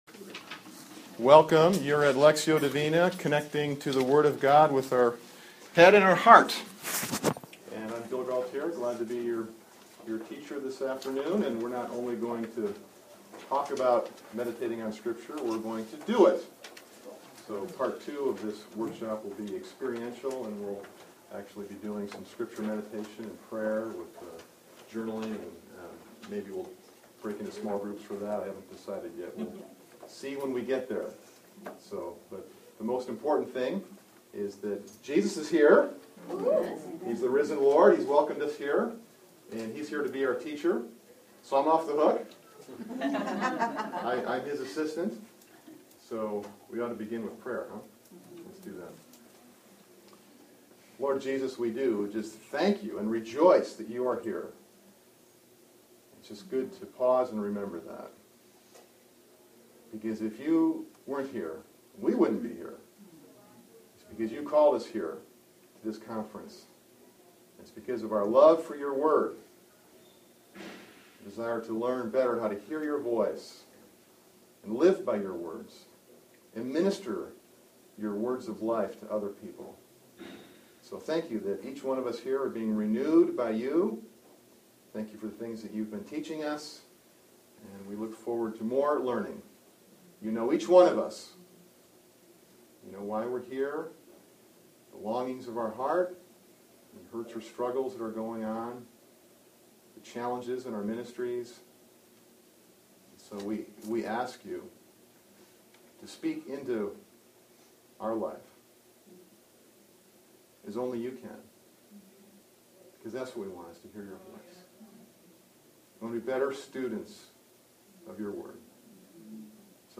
This workshop includes a Lectio Divina meditation on Matthew 8:5-13.